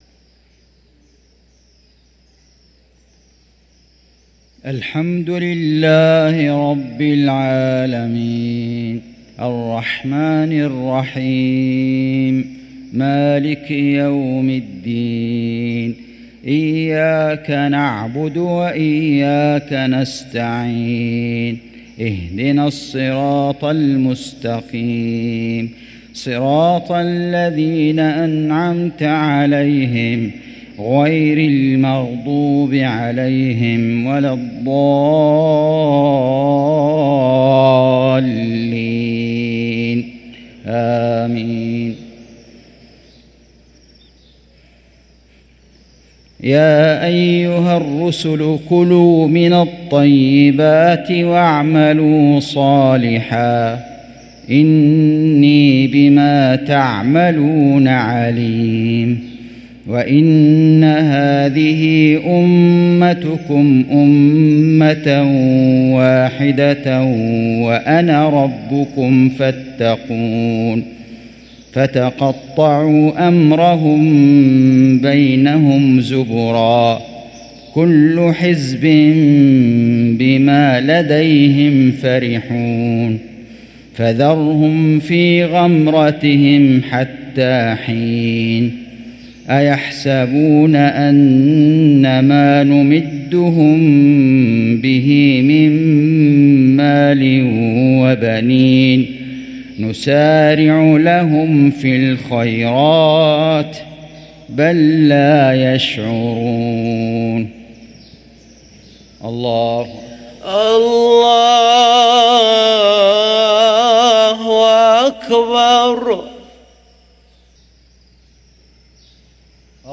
صلاة المغرب للقارئ فيصل غزاوي 22 جمادي الآخر 1445 هـ